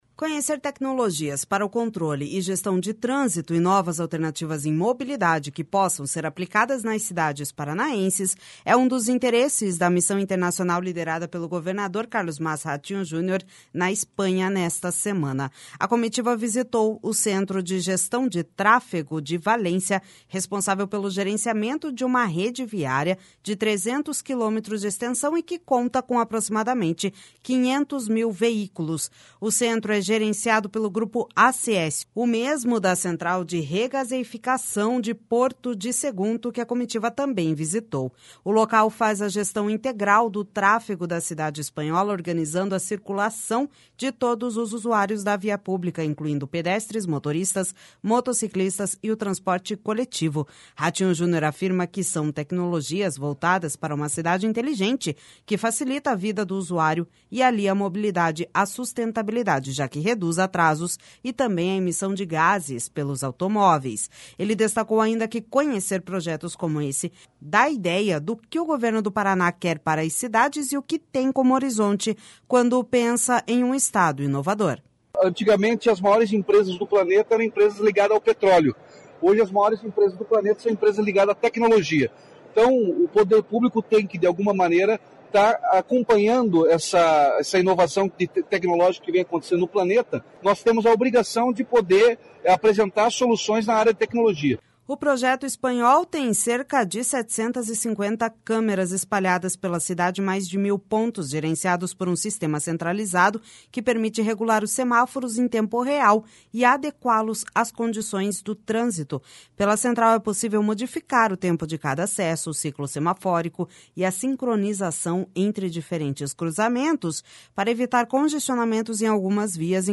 Ele destacou, ainda, que conhecer projetos como esse dá a ideia do que o Governo do Paraná quer para as cidades e o que tem como horizonte quando pensa em um estado inovador.// SONORA CARLOS MASSA RATINHO JUNIOR//O projeto espanhol tem cerca de 750 câmeras espalhadas pela cidade e mais de mil pontos, gerenciados por um sistema centralizado que permite regular os semáforos em tempo real e adequá-los às condições do trânsito.